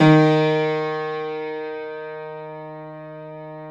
53d-pno07-D1.wav